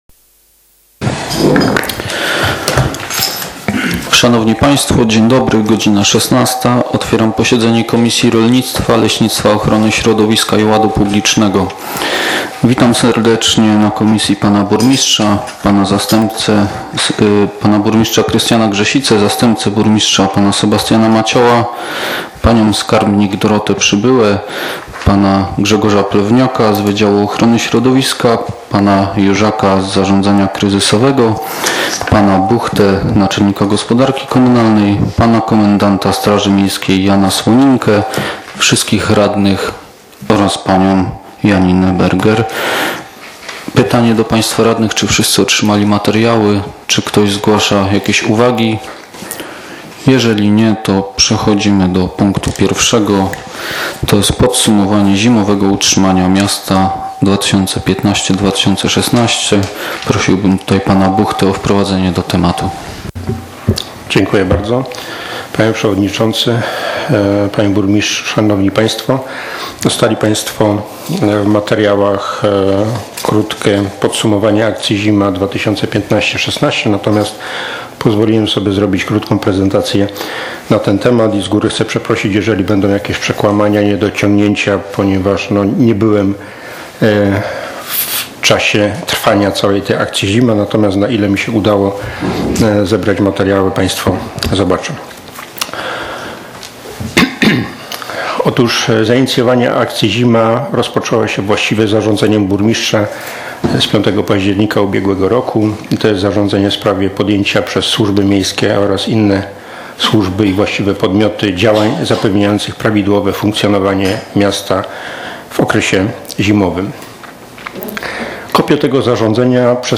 z posiedzenia Komisji Rolnictwa, Leśnictwa, Ochrony Środowiska i Ładu Publicznego w dniu 18.05.2016 r.